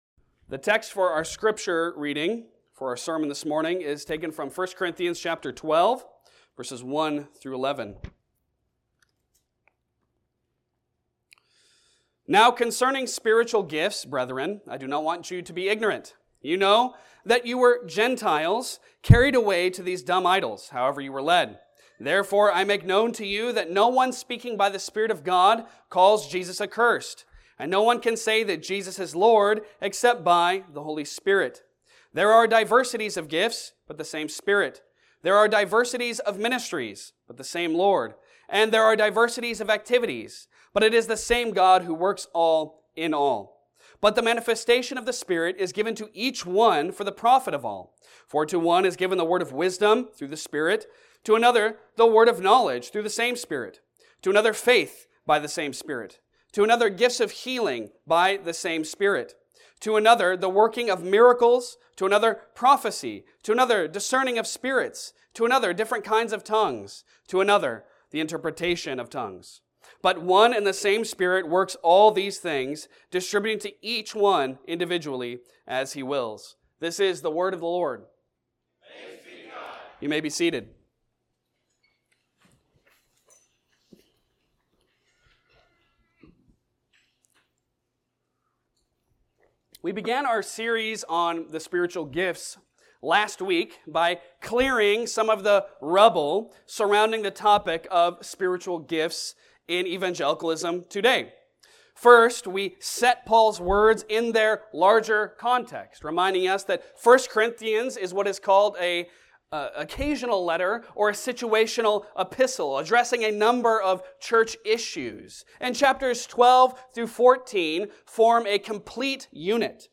Passage: 1 Corinthians 12:1-11 Service Type: Sunday Sermon